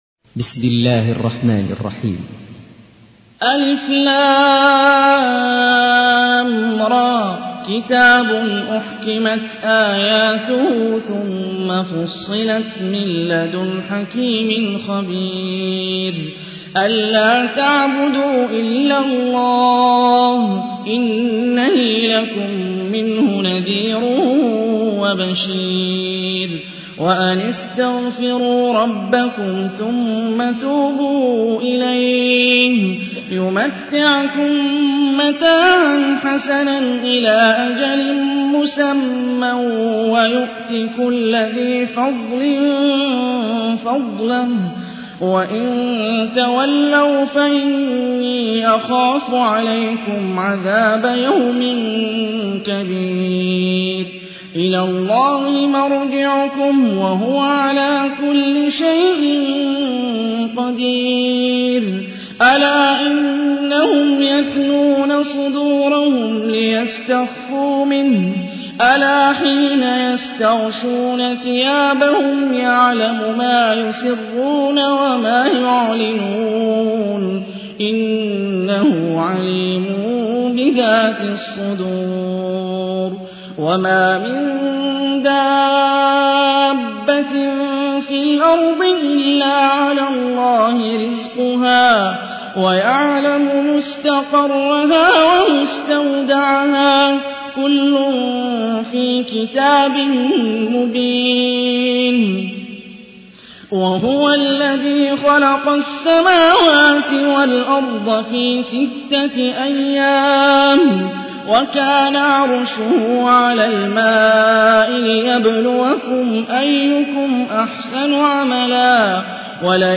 تحميل : 11. سورة هود / القارئ هاني الرفاعي / القرآن الكريم / موقع يا حسين